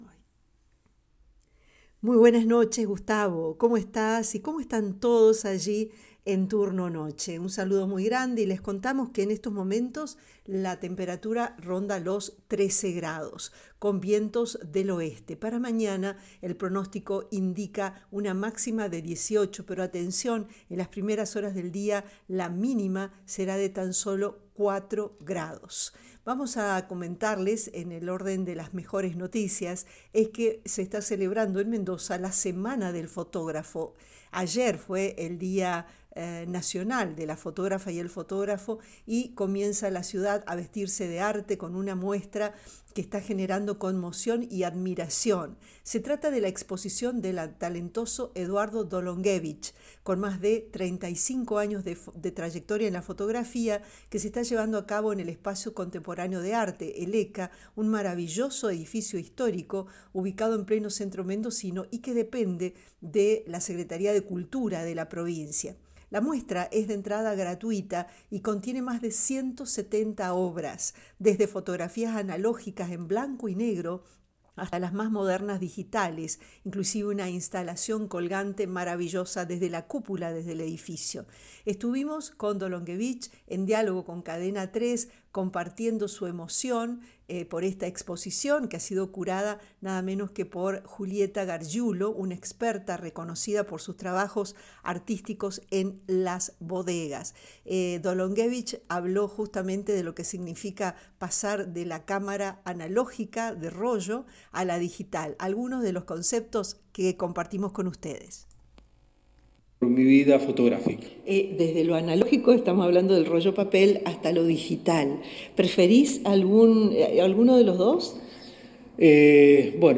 Audio. 3° gol de Rosario Central a Universidad Central (Copetti) - relato